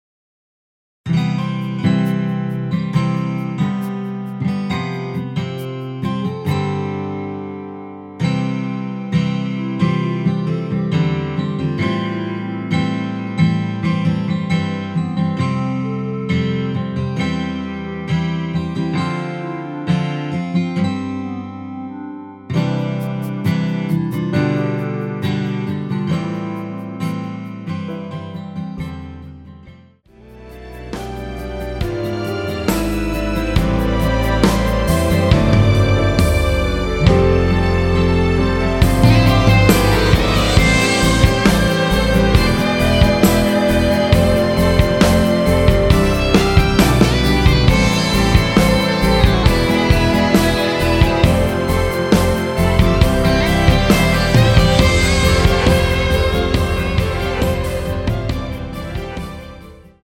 원키에서(-1)내린 (1절+후렴) 멜로디 포함된 MR입니다.(미리듣기 확인)
◈ 곡명 옆 (-1)은 반음 내림, (+1)은 반음 올림 입니다.
앞부분30초, 뒷부분30초씩 편집해서 올려 드리고 있습니다.
중간에 음이 끈어지고 다시 나오는 이유는